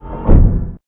dockingGearRetract.wav